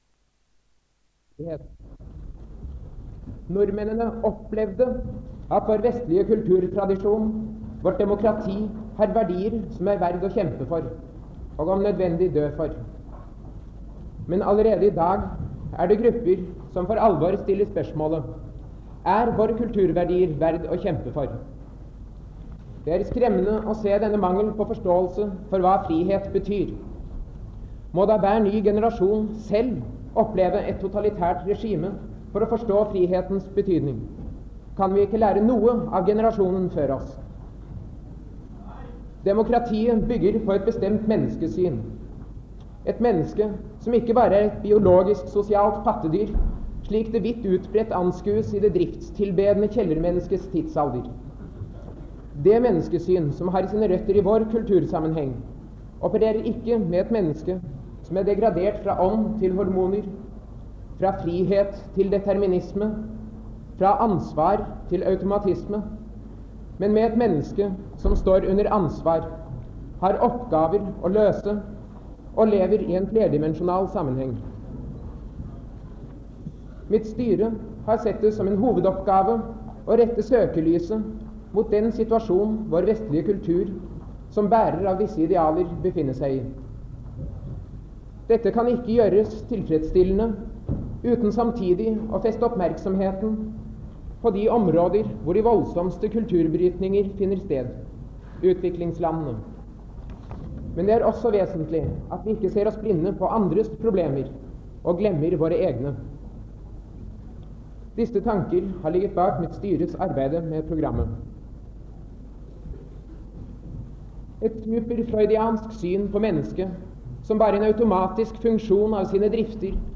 Det Norske Studentersamfund, Generalforsamling, 08.05.1965